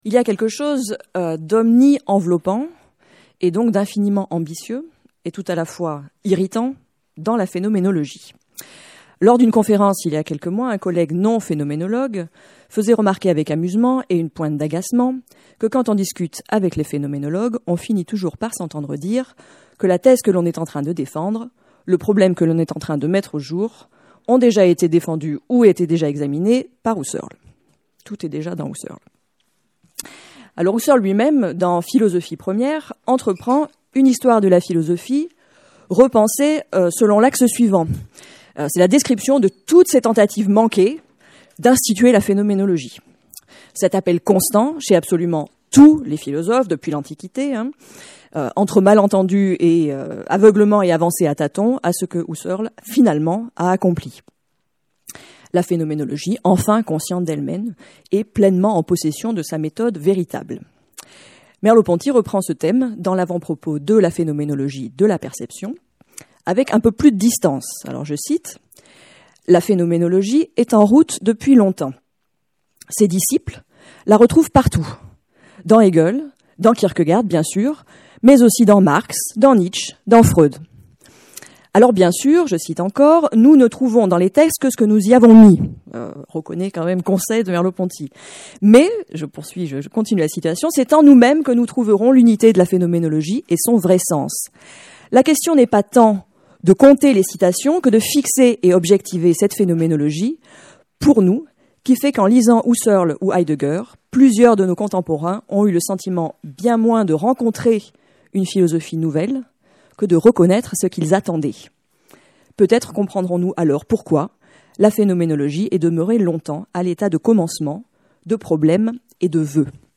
La question de la relation entre les sujets, leur incarnation et l'imaginaire sera au cœur de cette conférence. On peut contester l'idée que toute la phénoménologie repose sur le primat de la perception et de la présence.